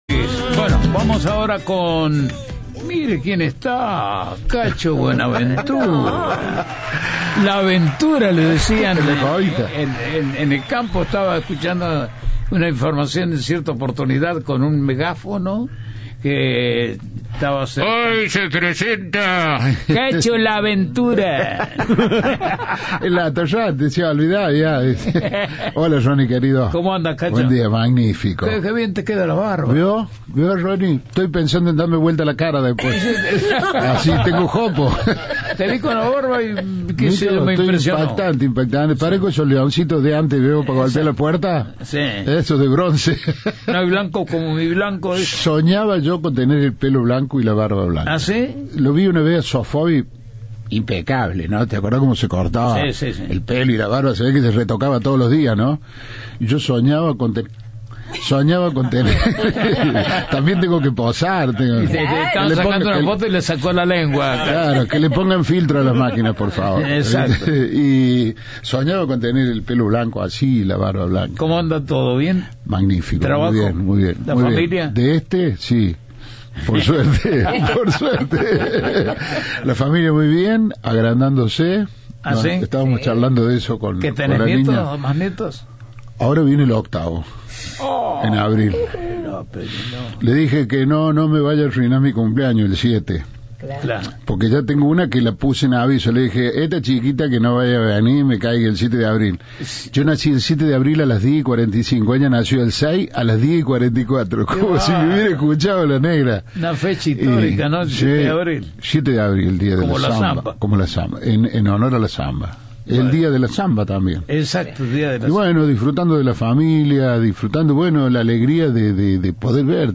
El artista estuvo presente en los estudios de Cadena 3 para darle su toque de humor a la mañana. Recibió mensajes de los oyentes de la gran cadena federal, contó anécdotas y divirtió a todo el equipo.